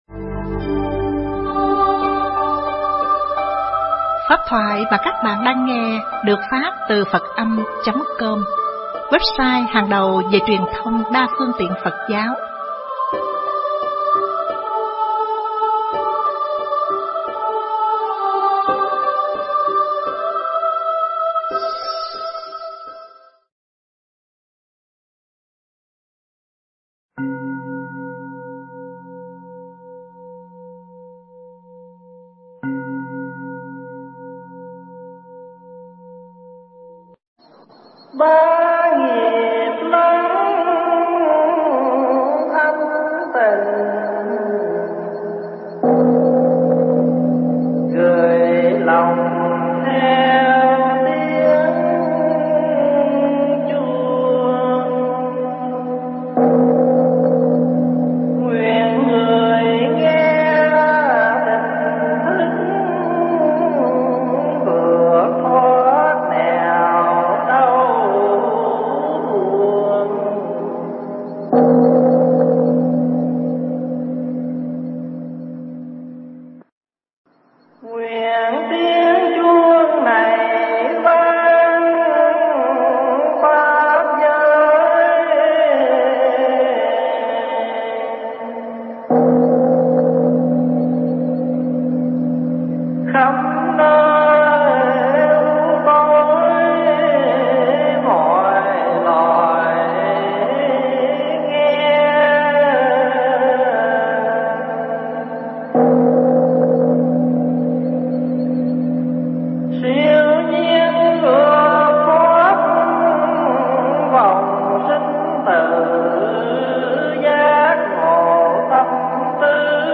Nghe Mp3 thuyết pháp Không Nên Có Tà Kiến (Vấn Đáp)